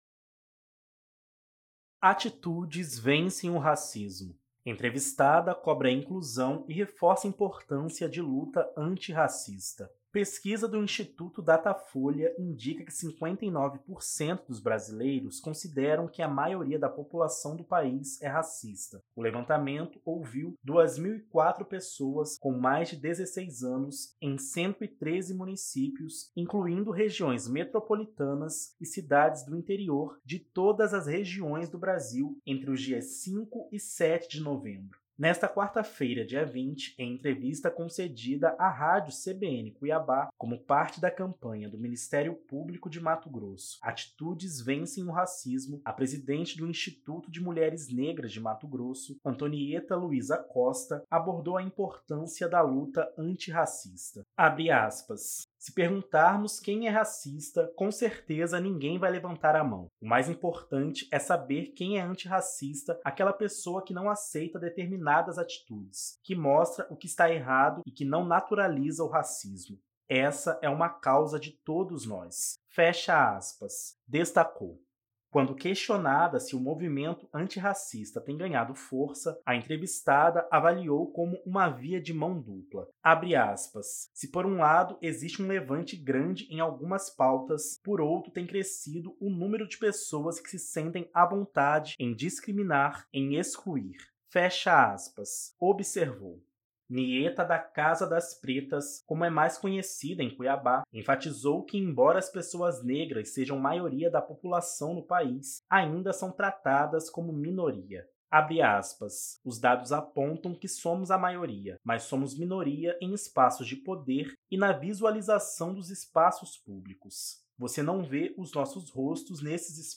A ação contempla também a realização de entrevistas em estúdio na Rádio CBN Cuiabá 95,9 FM, todas as quartas-feiras, com autoridades, lideranças e personalidades sobre a temática.